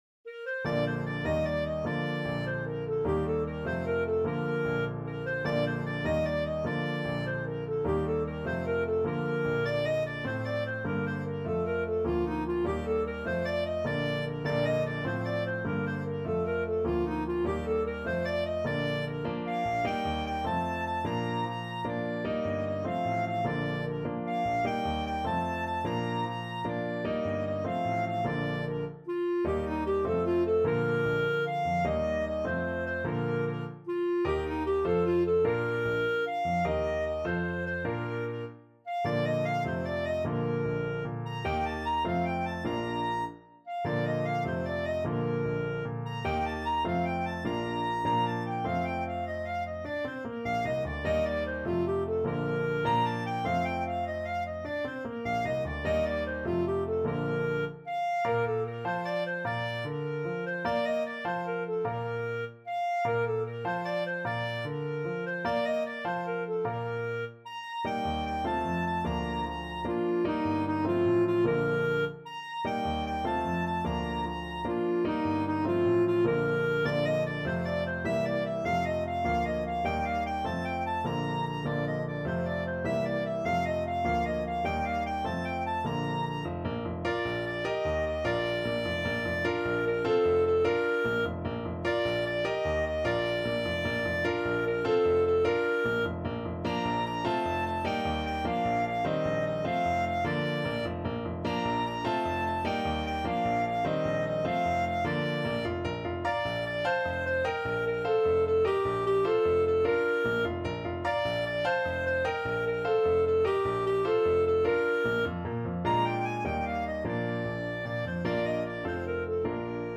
Clarinet version
6/8 (View more 6/8 Music)
Classical (View more Classical Clarinet Music)